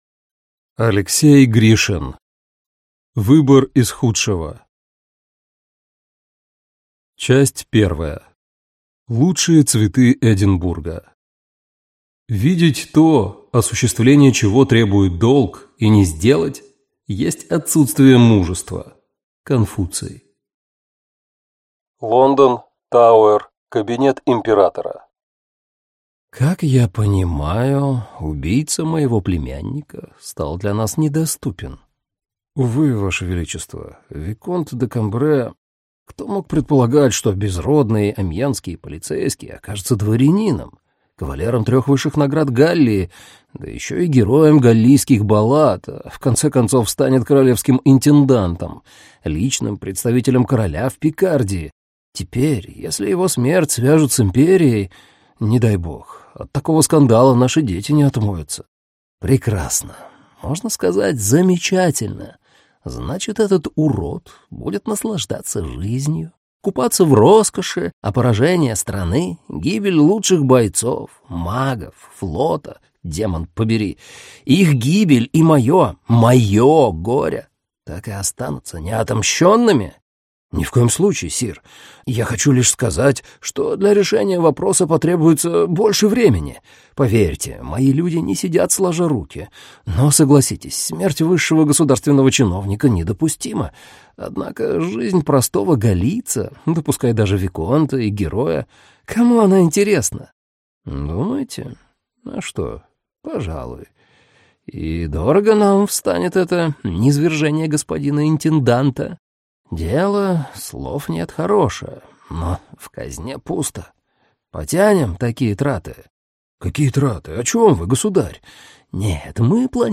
Аудиокнига Выбор из худшего | Библиотека аудиокниг
Прослушать и бесплатно скачать фрагмент аудиокниги